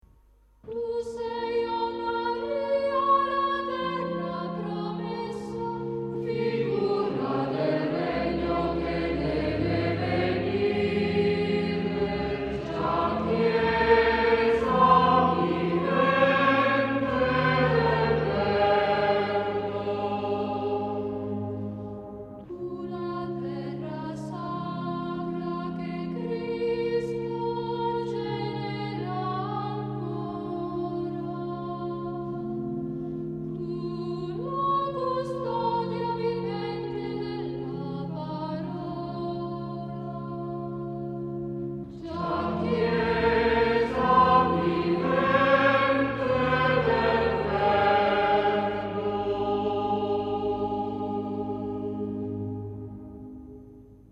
Primo responsorio 1,269 Mb   Ascolto